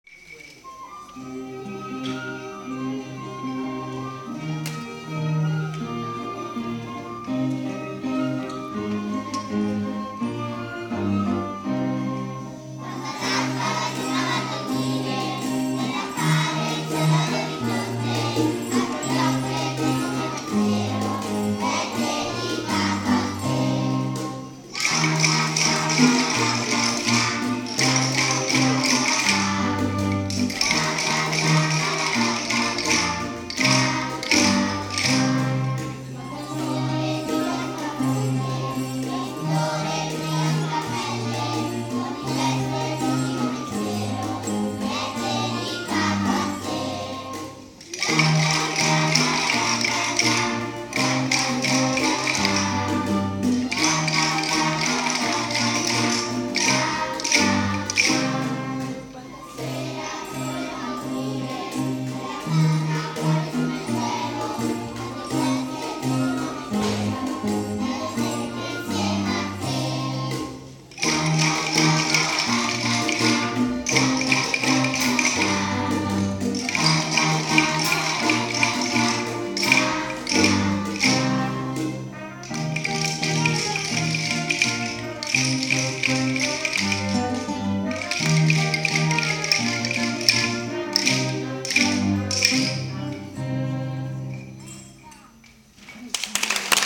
dedicato-a-te-orchestra-e-coro.mp3